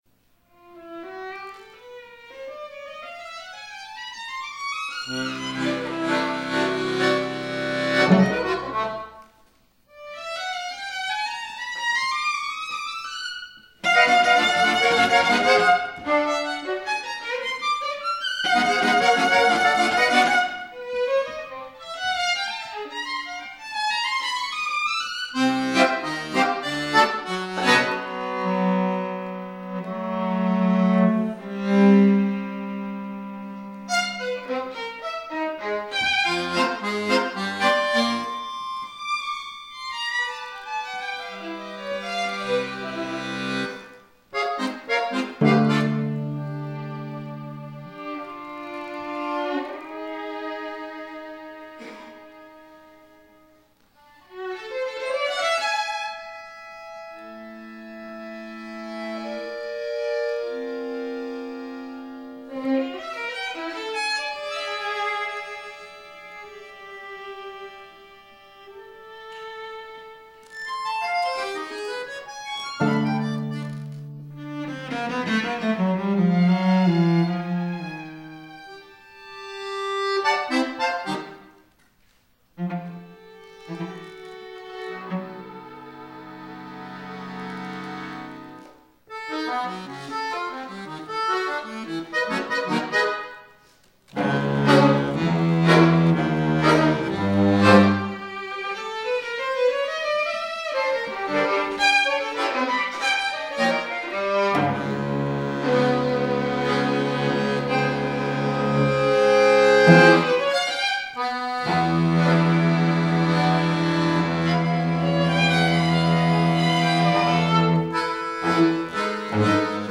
for Violin, Cello, and Accordion